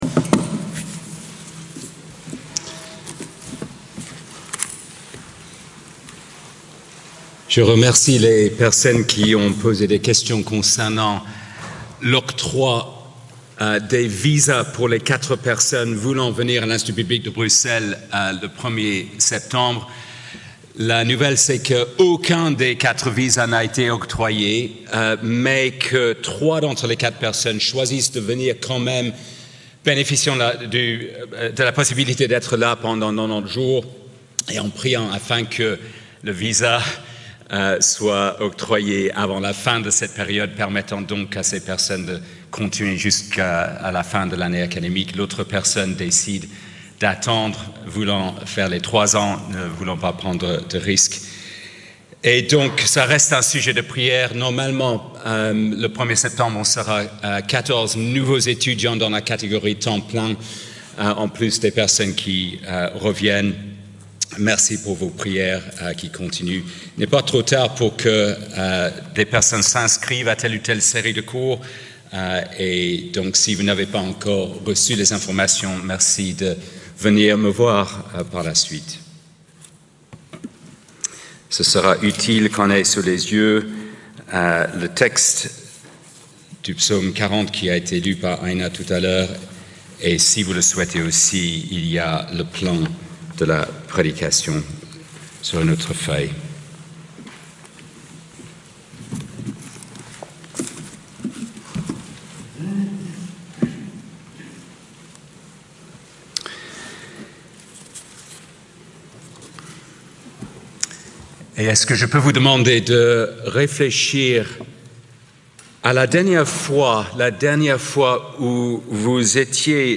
Plan de la prédication Introduction : dans la fosse 1. Louange (v. 2-6) 2. Consécration (v. 7-12) 3. Supplication (v. 13-18) Conclusion